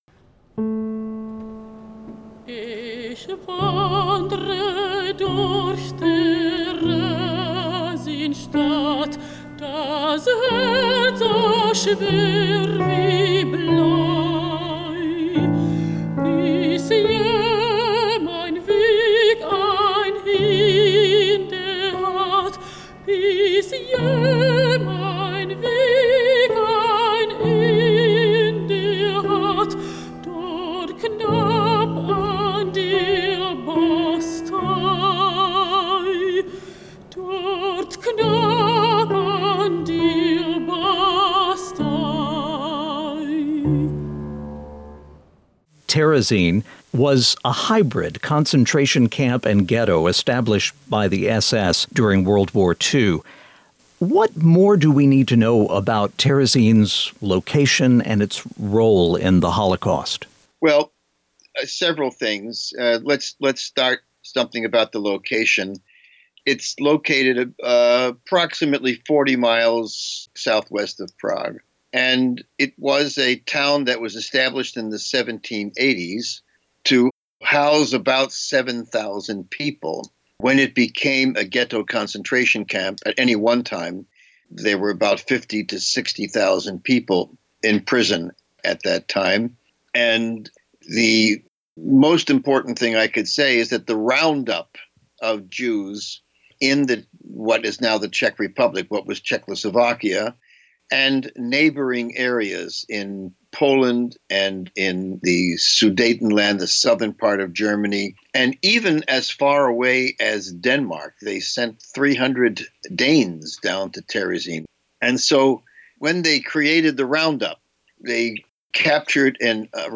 Audio Q&A
The music clips heard in the interview are excerpts from music written by composers interred at Terezin.